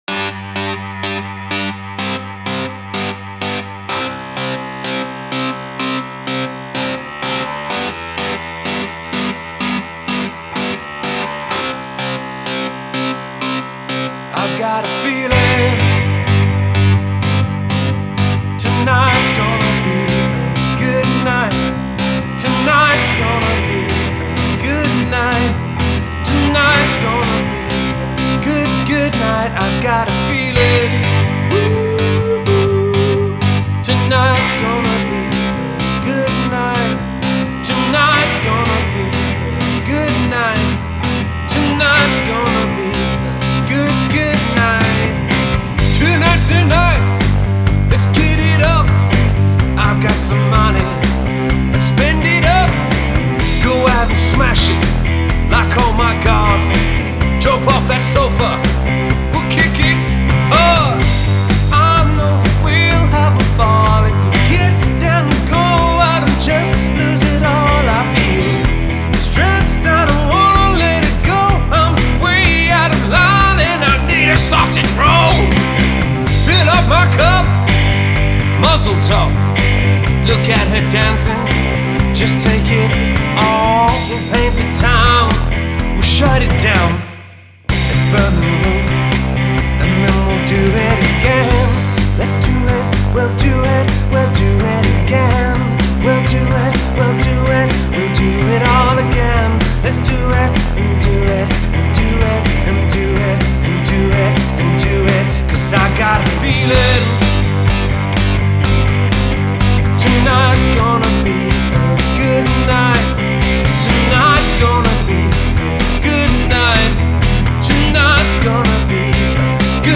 Dance/Soul